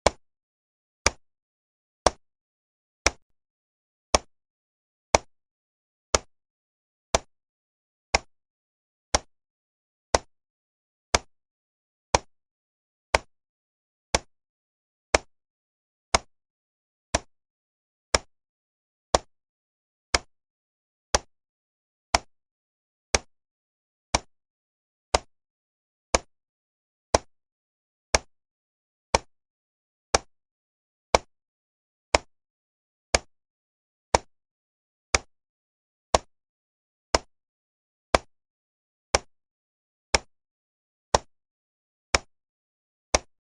Удары метронома в разных вариантах (для минуты молчания) и mp3 формате
9. Громкие удары метронома
zvuk-metronoma-42-udarov.mp3